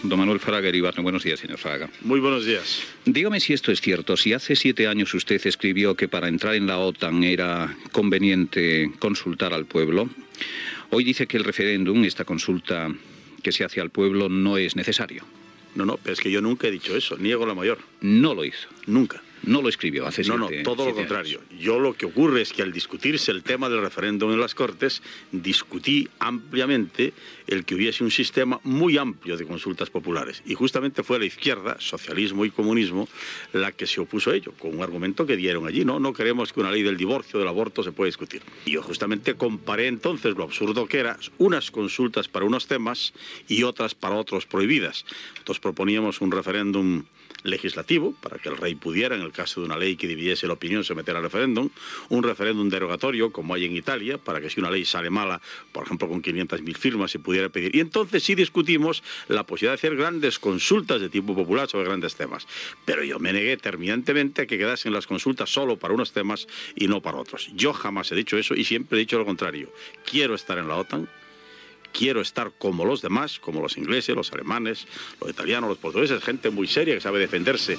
Fragment d'una entrevista al líder d'Alianza Popular, Manuel Fraga Iribarne, sobre el referèndum d'entrada a l'OTAN.
Info-entreteniment